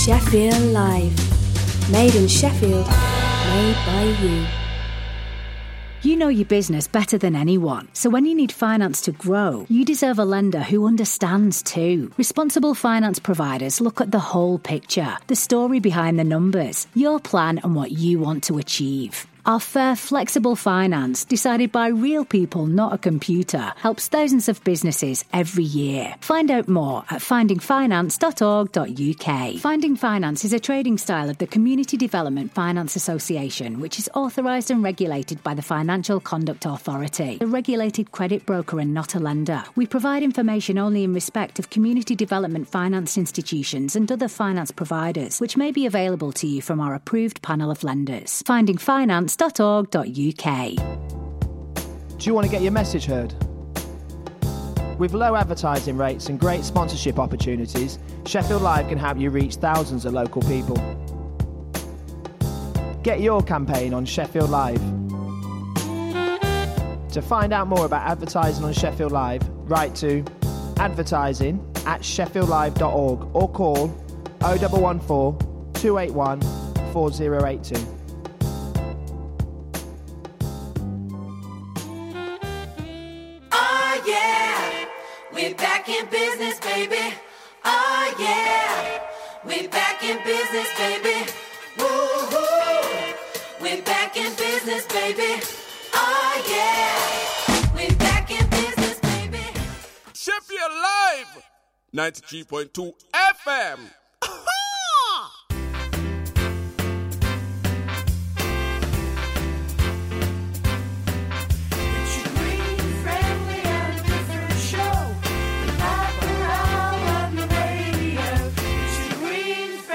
Information about radical environmental projects, innovative regeneration activities, views on the city’s development and off-the-wall cultural projects with a wide range of music from across the world.